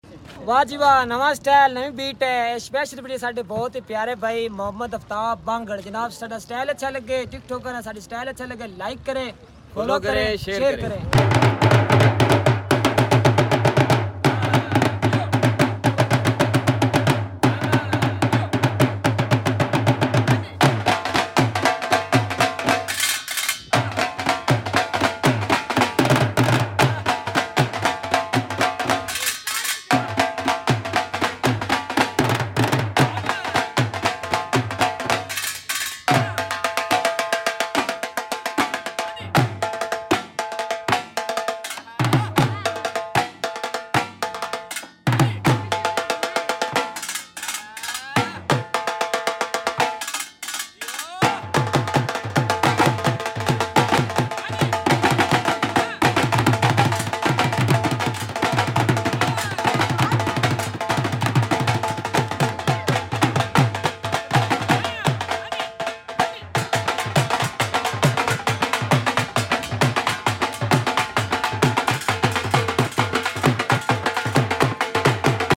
beautiful Dhol beat